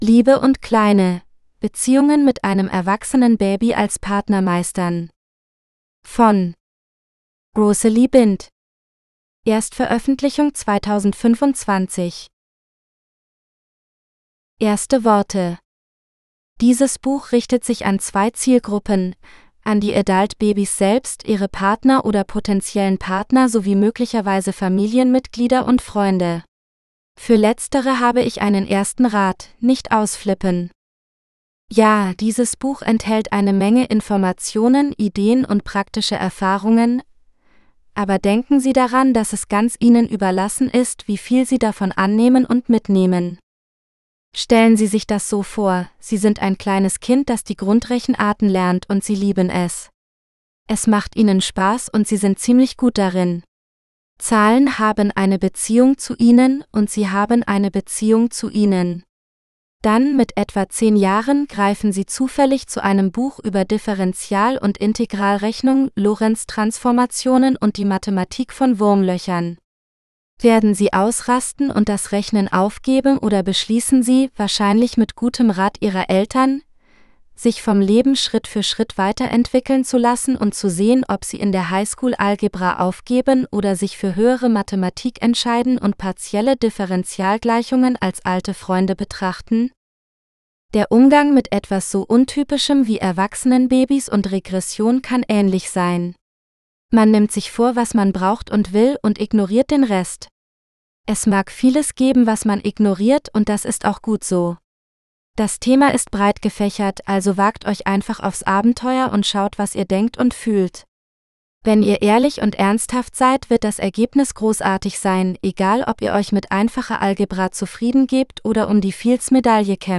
Love and Littles GERMAN – (AUDIOBOOK – female): $US6.50